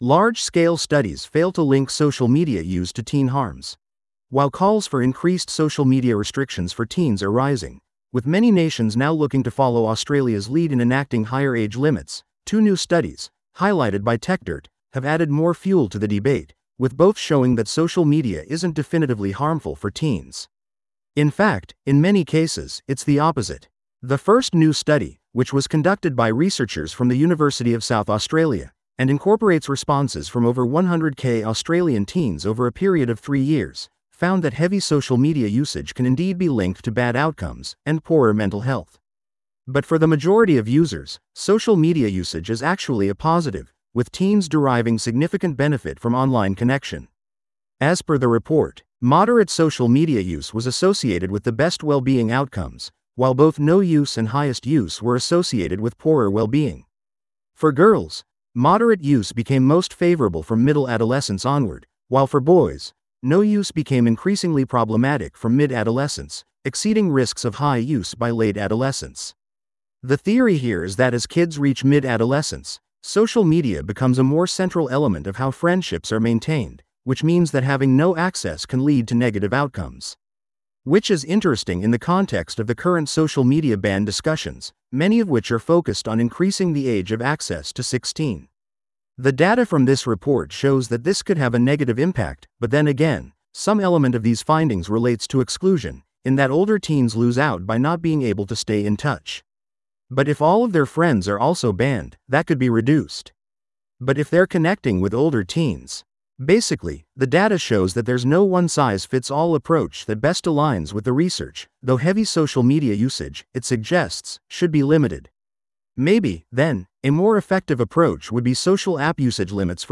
This audio is auto-generated.